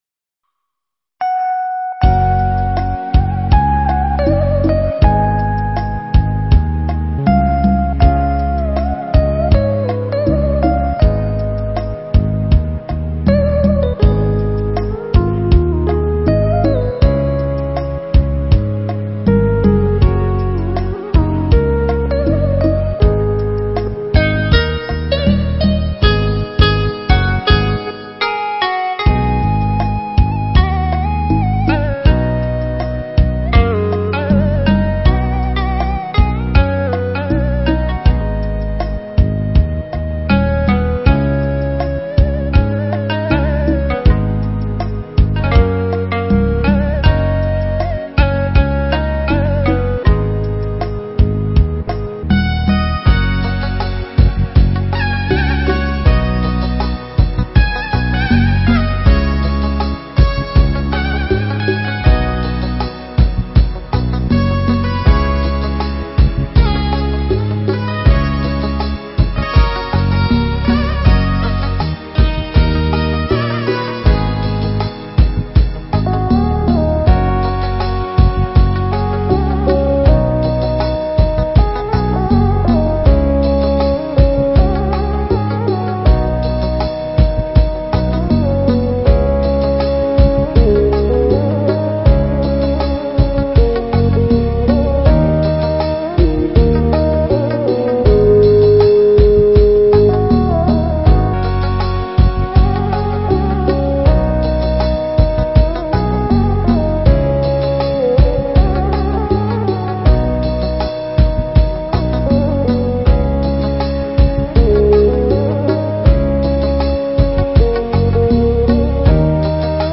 Mp3 Pháp Thoại Địa Ngục Có Hay Không?
giảng tại Chùa Tương Mai (quận Hoàng Mai, Hà Nội)